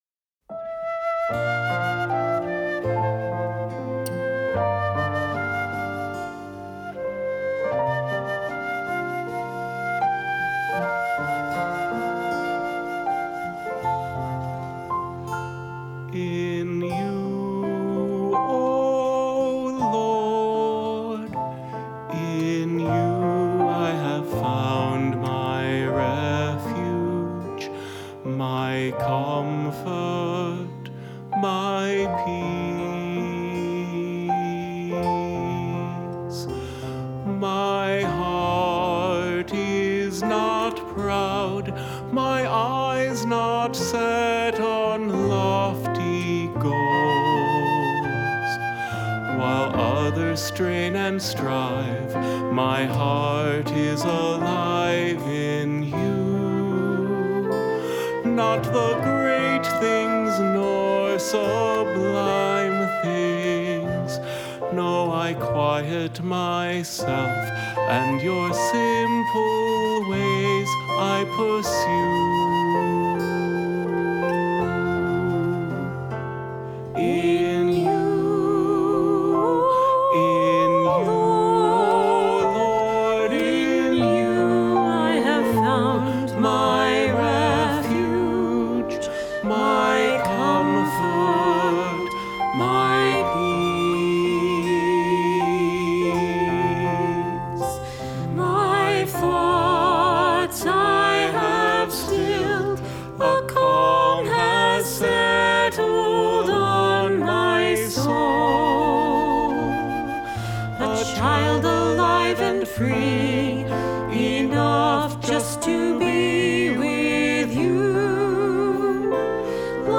Voicing: "Two-part mixed","Assembly"